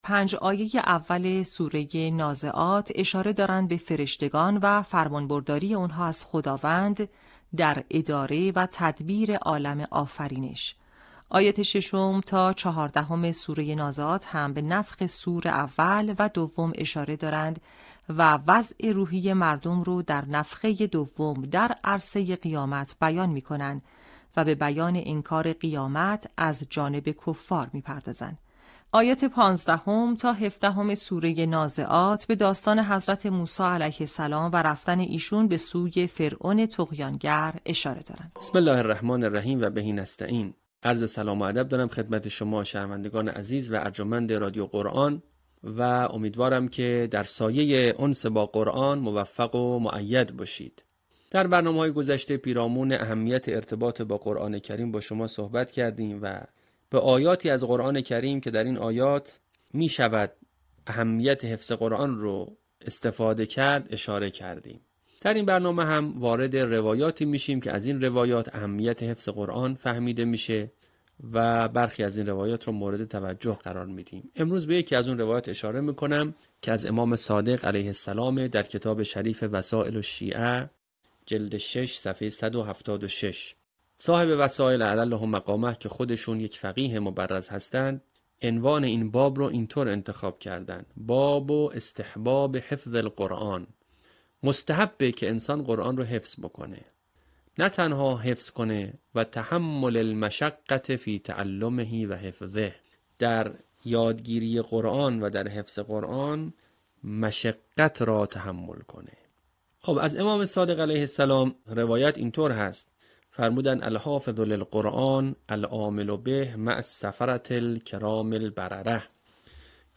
صوت | آموزش حفظ جزء ۳۰، آیات ۱ تا ۱۷ سوره نازعات